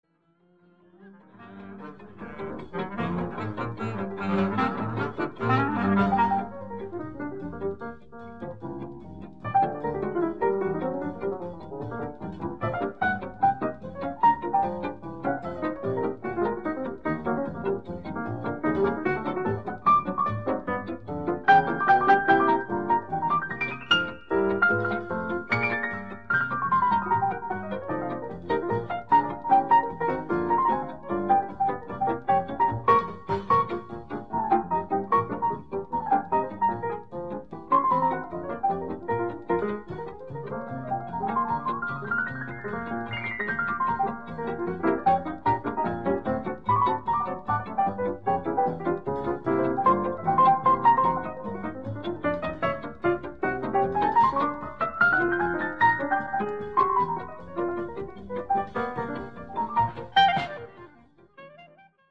jazz piano